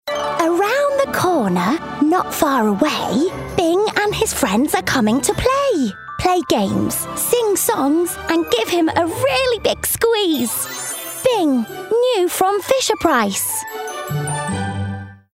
• Female
Bright, youthful, fun.